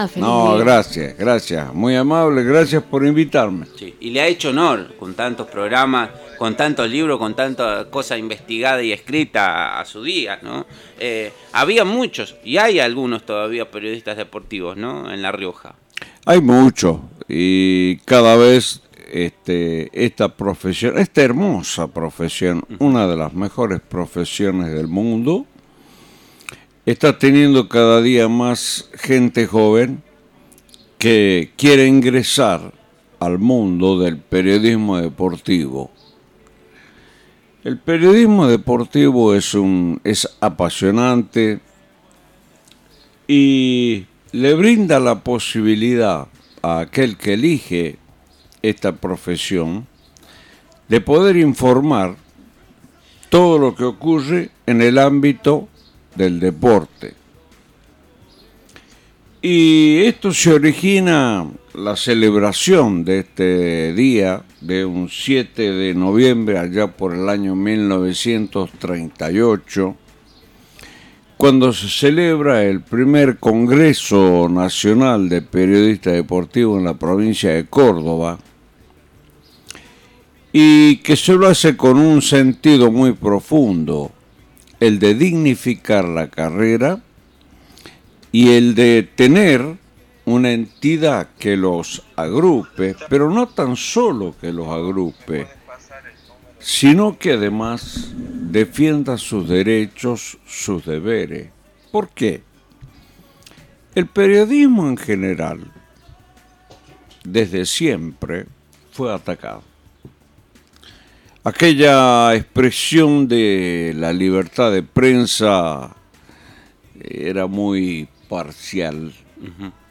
Durante la charla